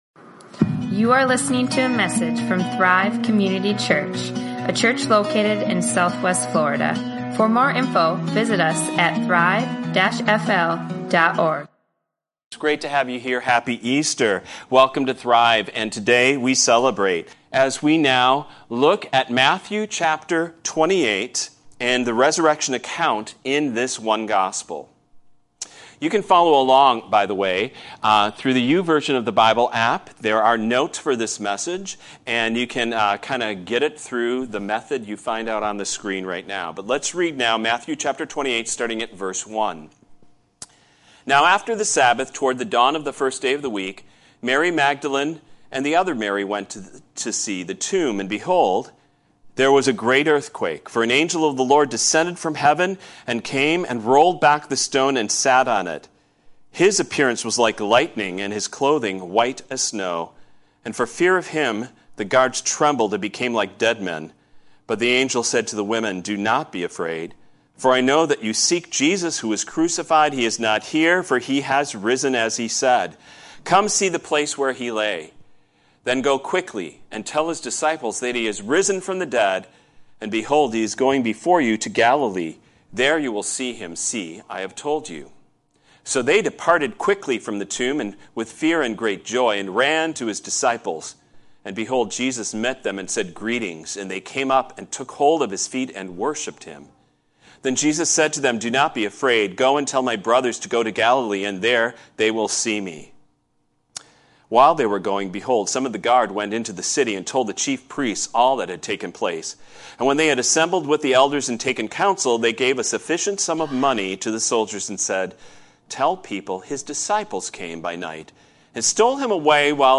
Easter 2021 | Sermons | Thrive Community Church